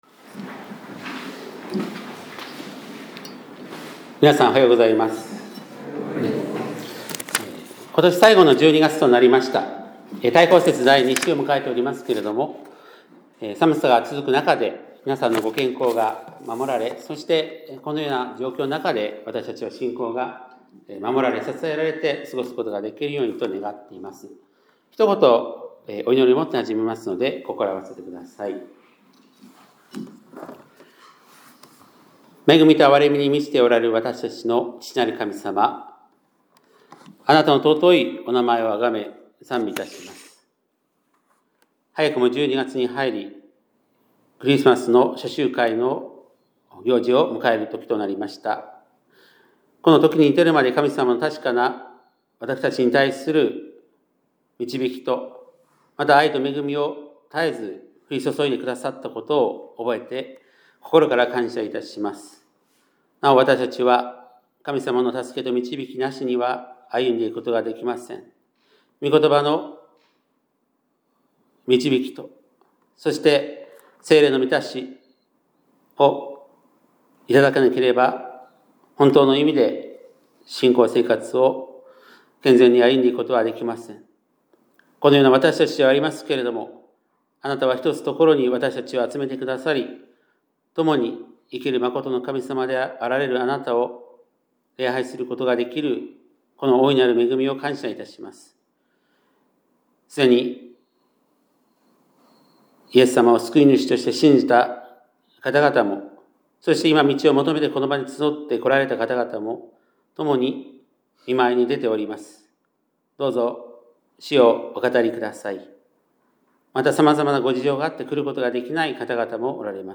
2025年12月7日（日）礼拝メッセージ - 香川県高松市のキリスト教会
2025年12月7日（日）礼拝メッセージ